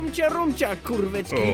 Play, download and share ciumcia rumcia kurwecki original sound button!!!!
gruby-nie-zyje-ale-po-polsku-pl-dubbing.mp3